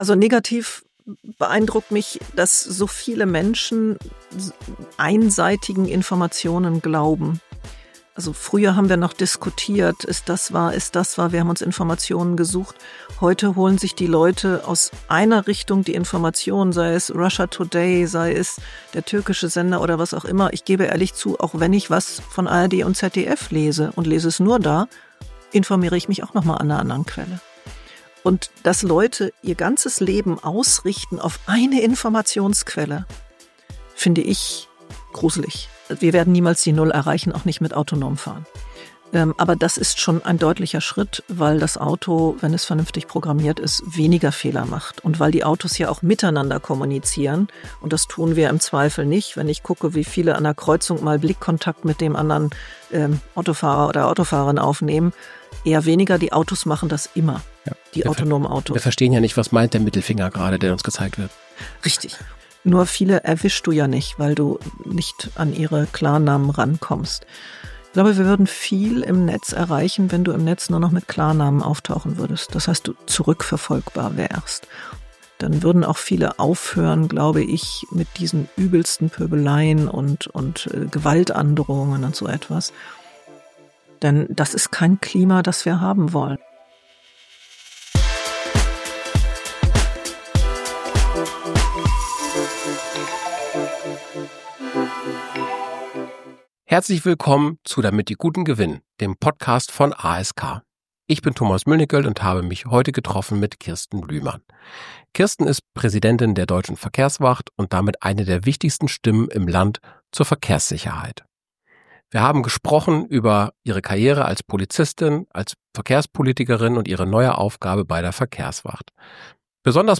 Kirsten Lühmann, Präsidentin der Deutschen Verkehrswacht, spricht in dieser Folge von „Damit die Guten gewinnen" über ihren beeindruckenden Weg von einer der ersten Polizistinnen Niedersachsens zur führenden Verkehrssicherheitsexpertin.